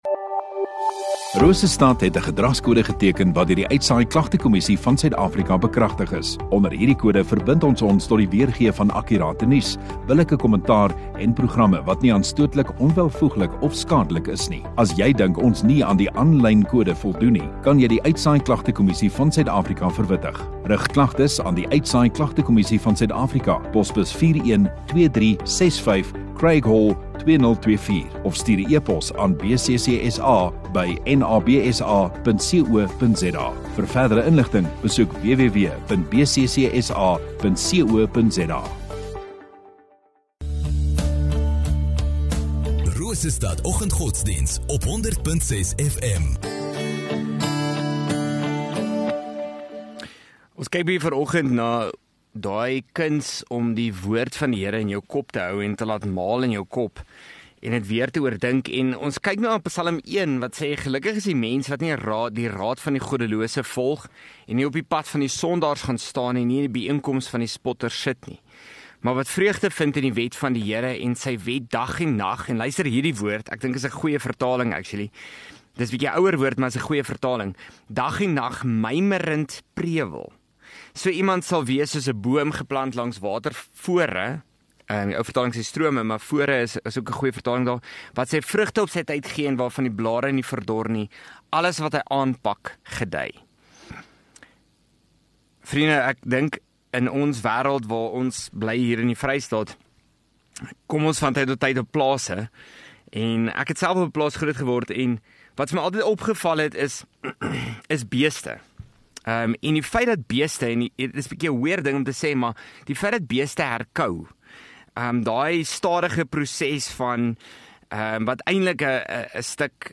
Oggenddiens